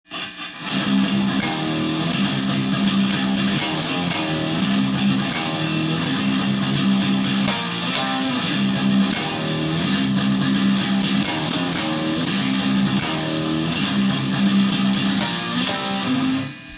ギターリフを考える
全音１音下げで６弦ののみ更に１音下げにしてみました。
こんな感じで危険な香りがするヘビーなリフが完成しました。